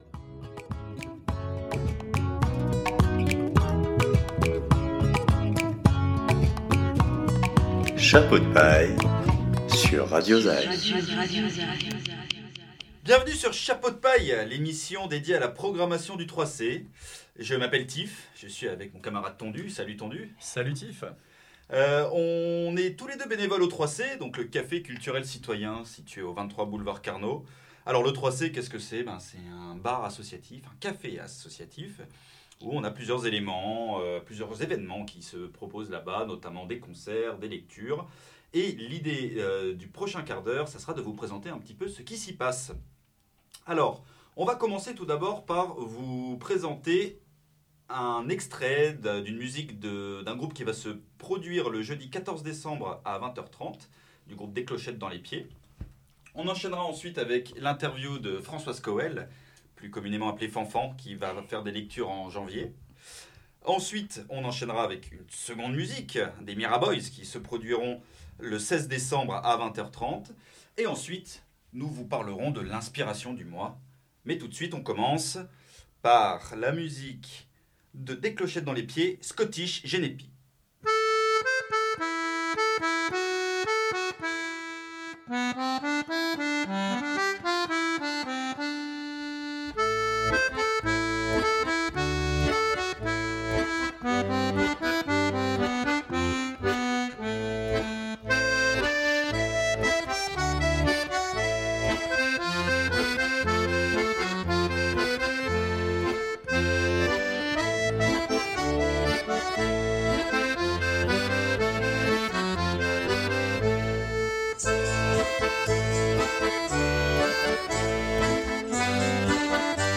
Au menu, la programmation du café pour ce mois-ci, avec des interviews d’artistes et des extraits de spectacles à venir !